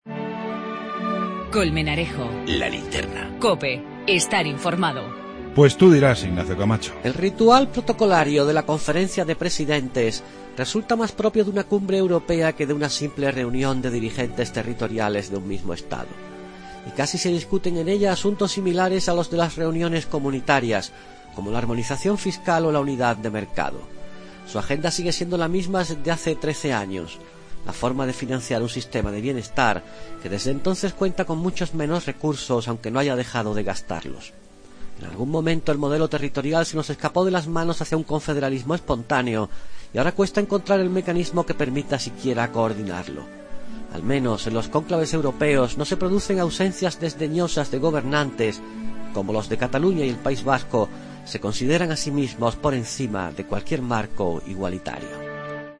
El comentario de Ignacio Camacho sobre la Conferencia de Presidentes autonómicos